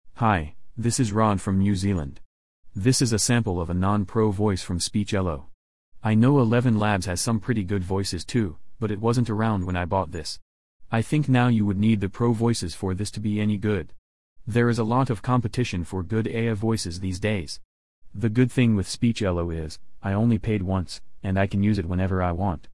The above sound file is a sample of the low-level membership voice you get with Speechelo.
You would need Pro level for this to be any good.
This couldn’t even say (ai) and to get it to pronounce Speechelo I had to write it as Speech-elo
Speechelo-sample-voice.mp3